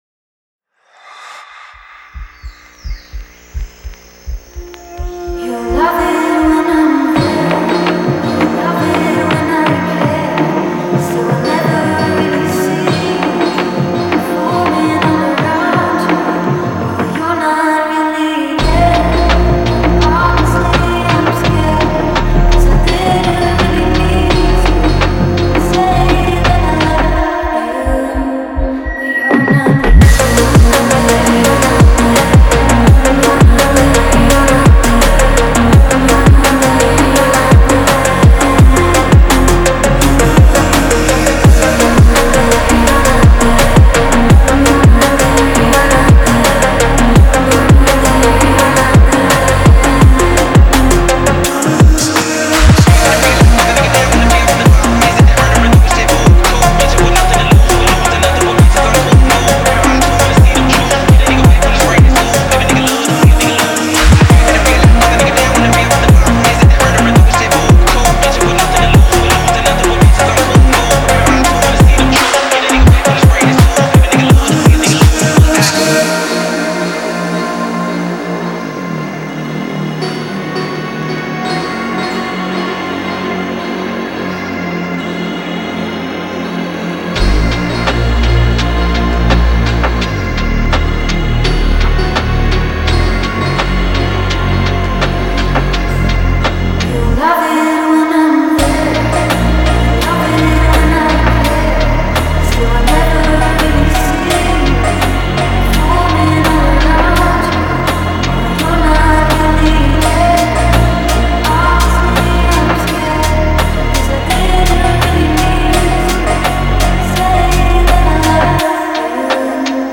Фонк музыка
Phonk